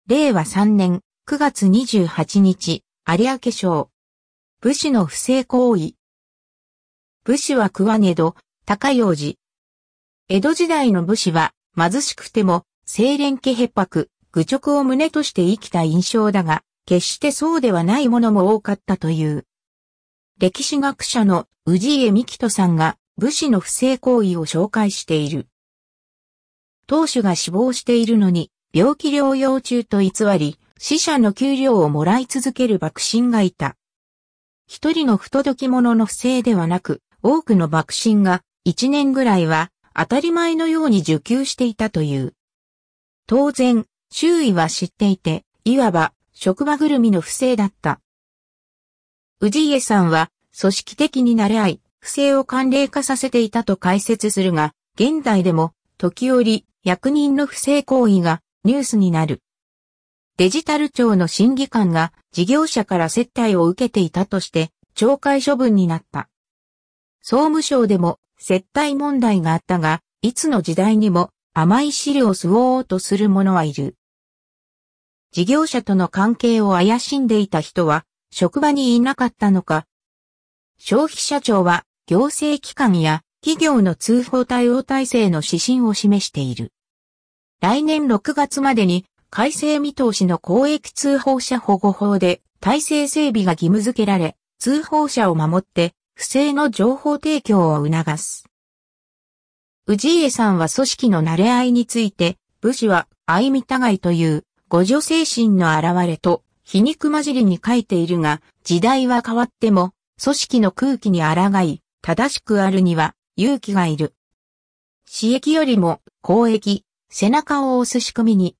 下記のボタンを押すと、AIが読み上げる有明抄を聞くことができます。